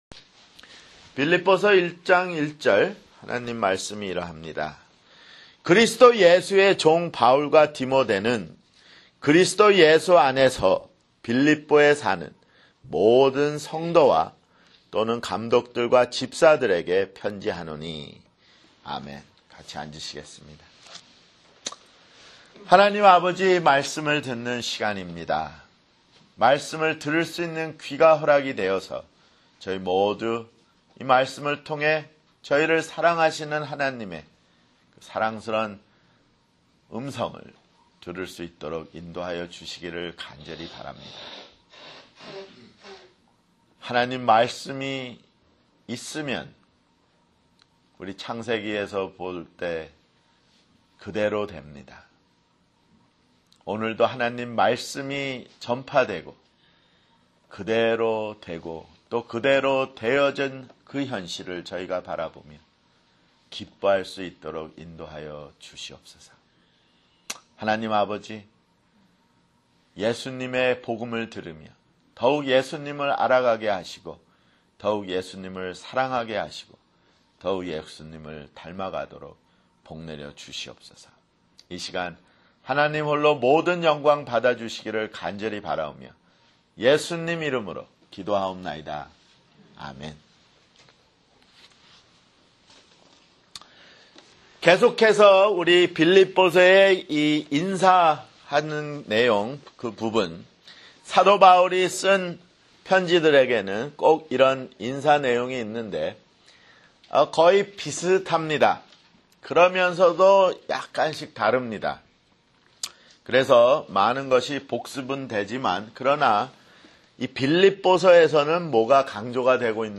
[주일설교] 빌립보서 (4)